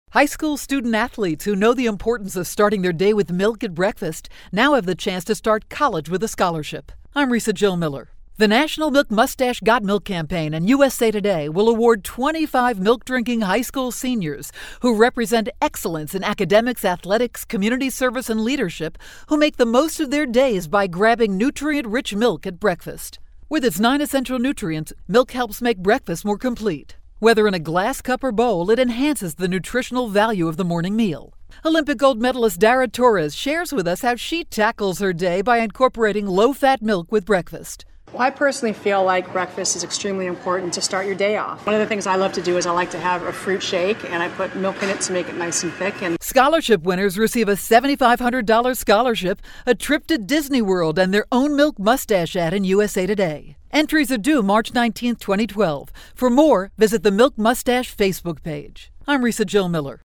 January 27, 2012Posted in: Audio News Release